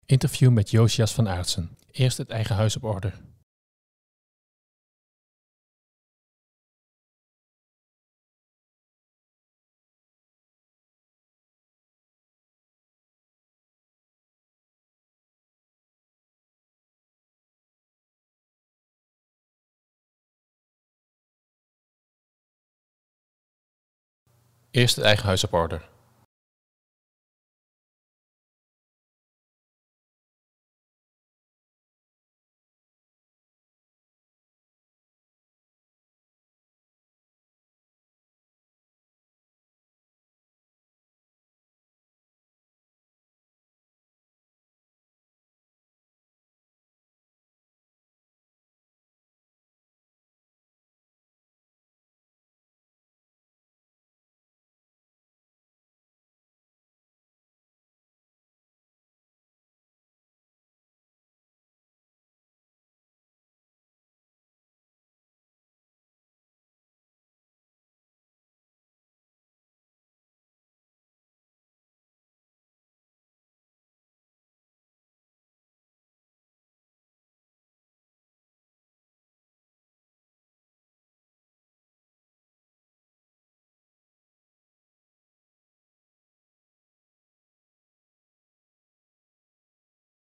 Interview met Jozias van Aartsen